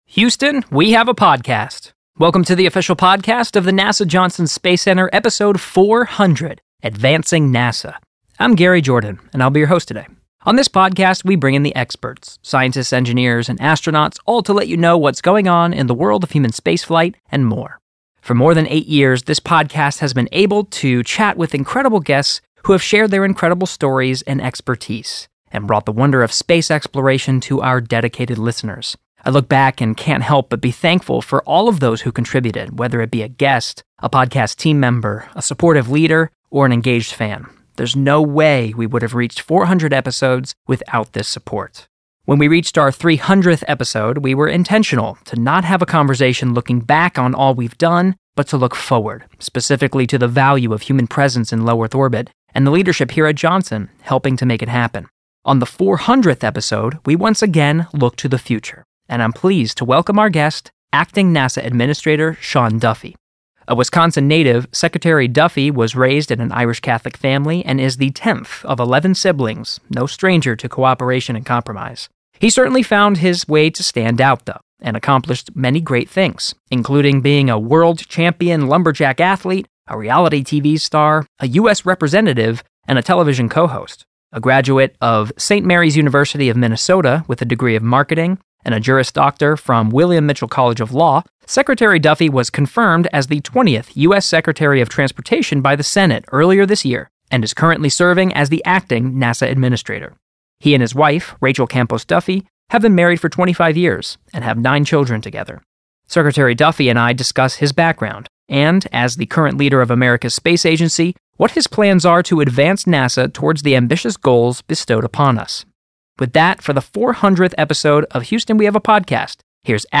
Listen to in-depth conversations with the astronauts, scientists and engineers who make it possible.
Acting NASA Administrator Sean Duffy joins us for our milestone 400th episode to share his journey and vision for advancing NASA into the future.